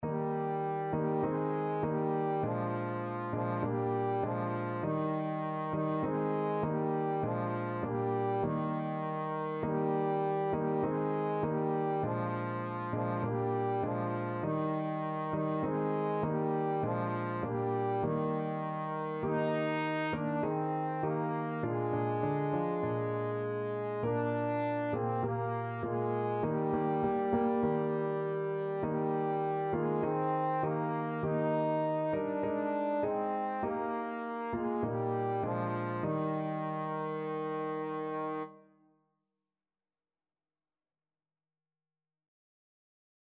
4/4 (View more 4/4 Music)
Eb4-Eb5
Classical (View more Classical Trombone Music)